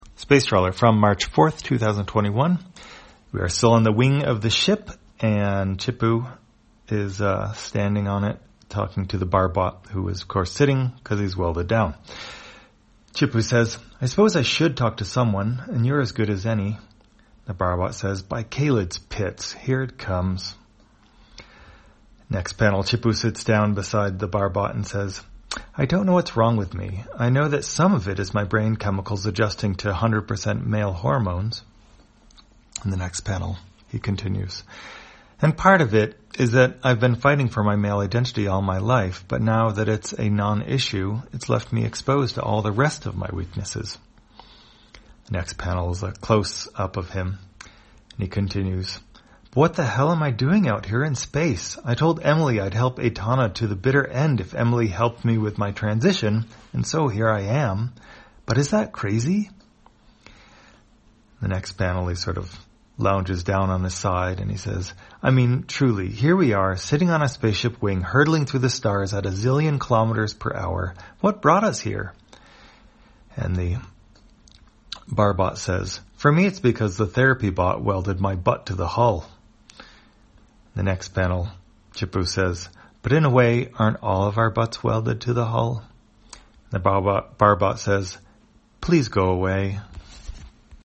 Spacetrawler, audio version For the blind or visually impaired, March 4, 2021.